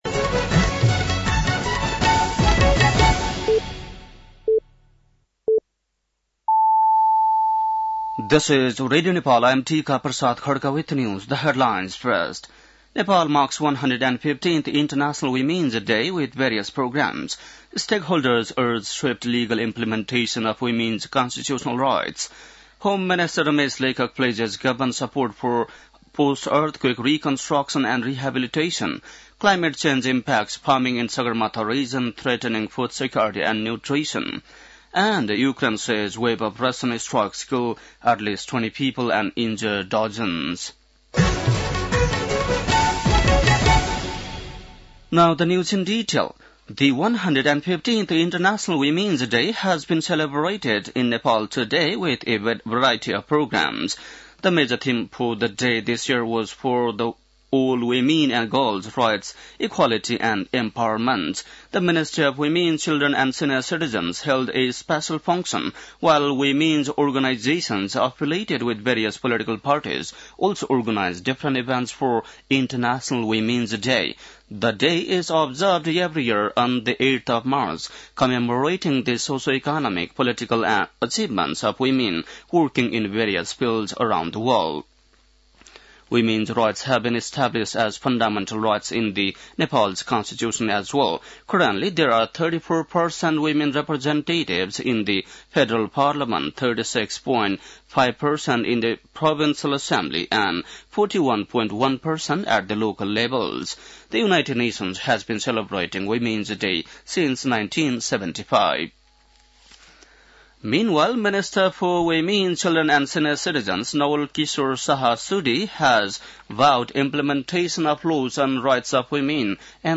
बेलुकी ८ बजेको अङ्ग्रेजी समाचार : २५ फागुन , २०८१
8-pm-news.mp3